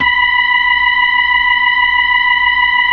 Index of /90_sSampleCDs/Roland LCDP10 Keys of the 60s and 70s 2/B-3_Brite Fast L/B-3_Brite Fast L